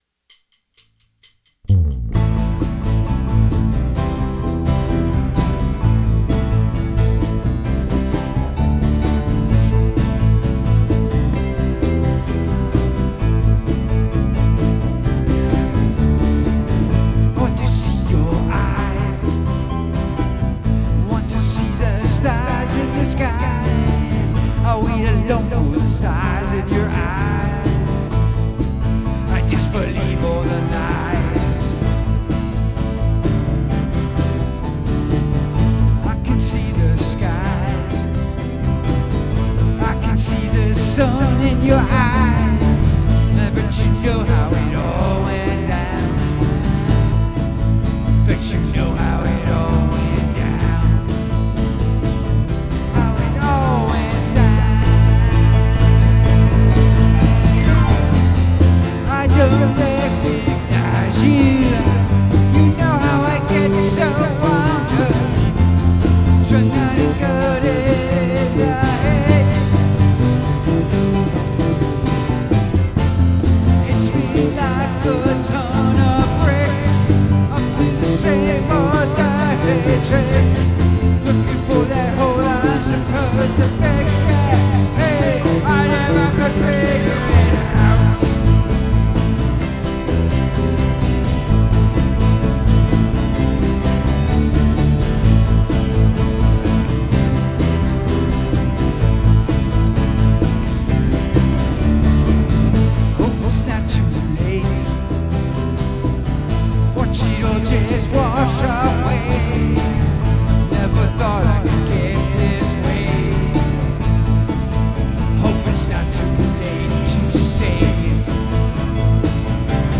Post Your Analog Recordings Here...
Wow! Your recording and mix sounds great. Sounds like we both go for the exact same drum aesthetic.